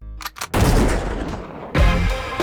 BLAST6.WAV